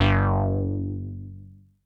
RUBBER MOOG.wav